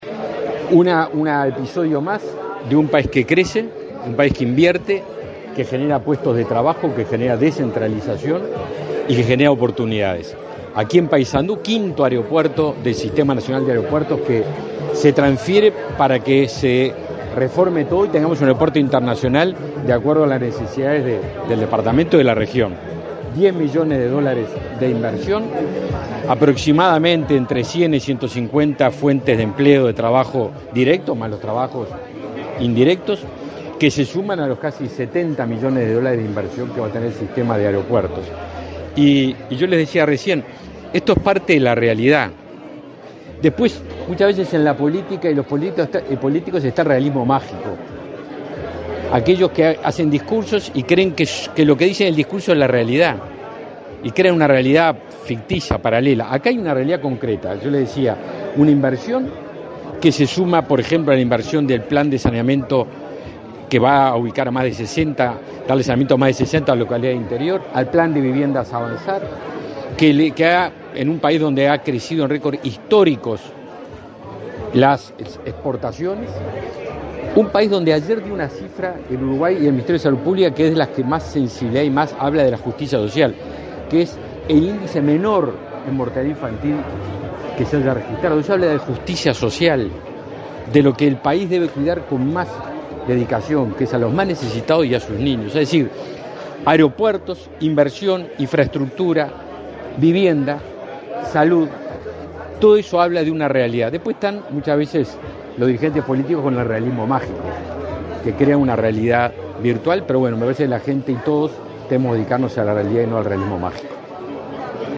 Declaraciones a la prensa del ministro de Defensa Nacional, Javier García
Declaraciones a la prensa del ministro de Defensa Nacional, Javier García 08/02/2023 Compartir Facebook Twitter Copiar enlace WhatsApp LinkedIn Tras el acto de traspaso de la gestión de la terminal aeroportuaria de Paysandú a la empresa Aeropuertos Uruguay, este 8 de febrero, el ministro de Defensa Nacional, Javier García, realizó declaraciones a la prensa.